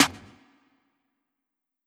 Snare (All I Know).wav